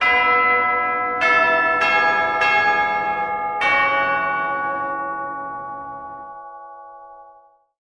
145-soundeffect-school-bells.mp3